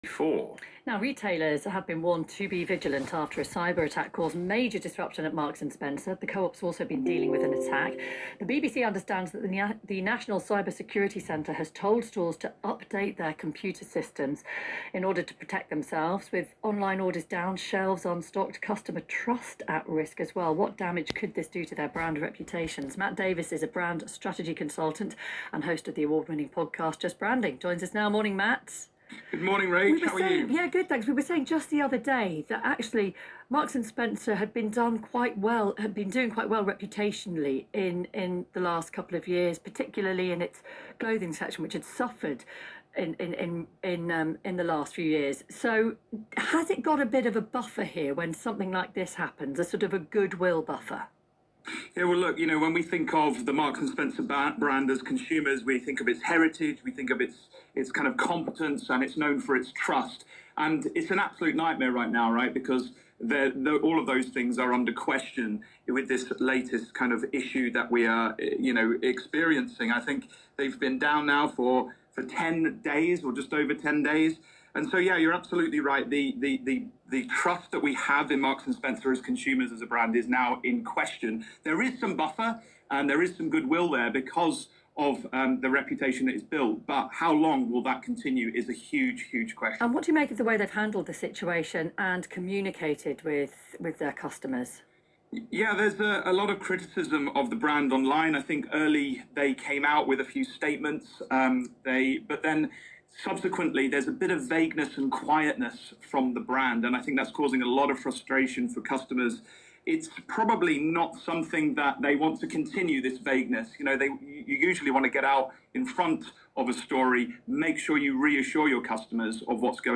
BBC 5 Live Interview - M&S Brand Reputation